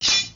nanoBladeImpact.wav